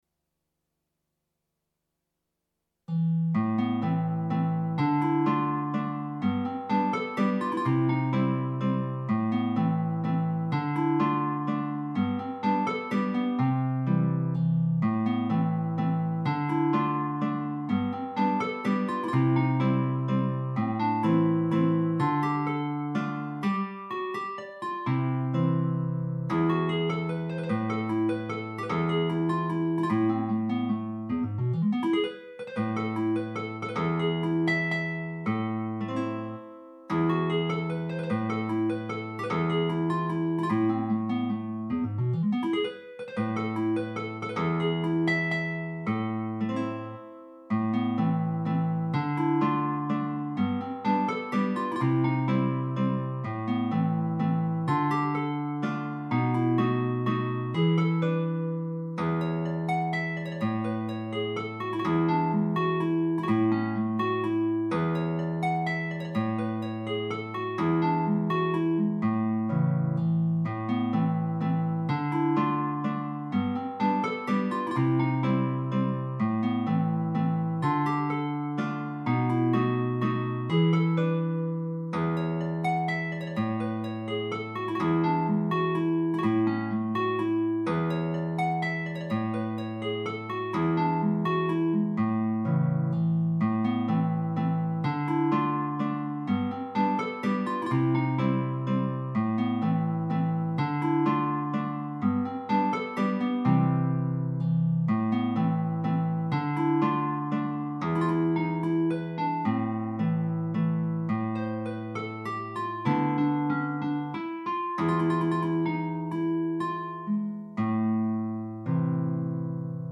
Guitar Solo